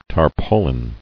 [tar·pau·lin]